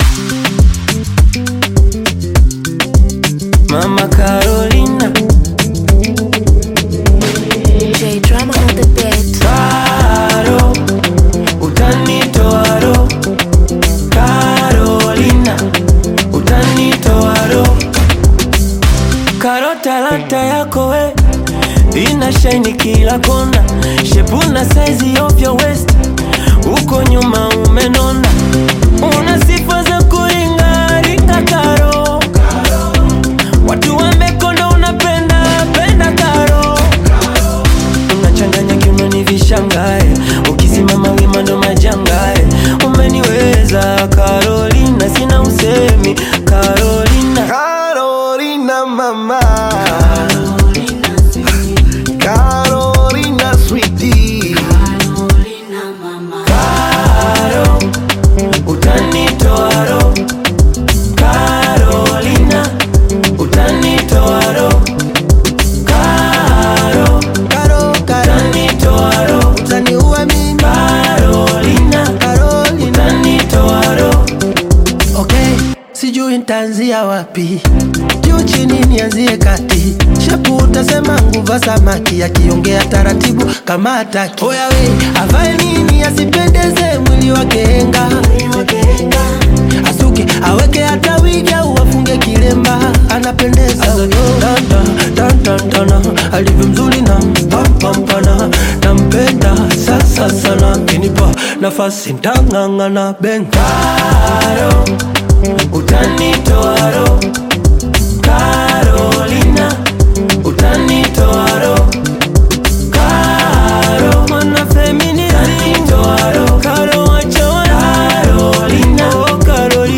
Bongo Flava music track
Bongo Flava